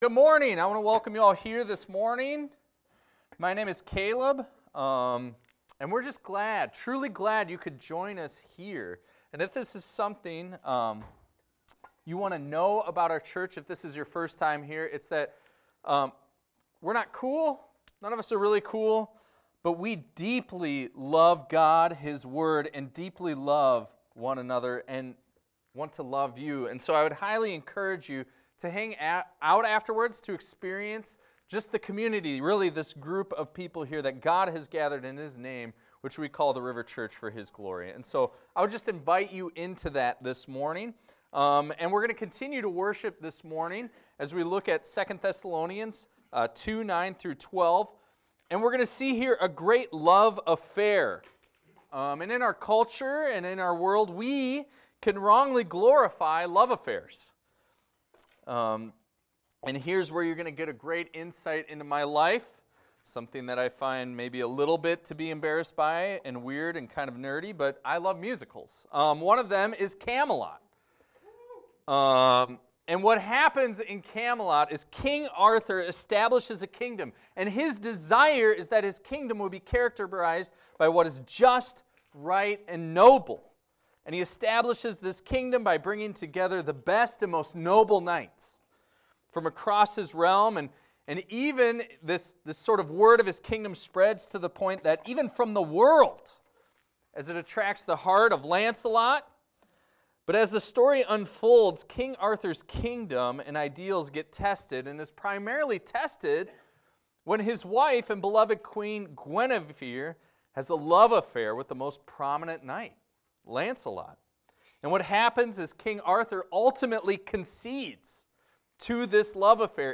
This is a recording of a sermon titled, "Do You Love the Truth?."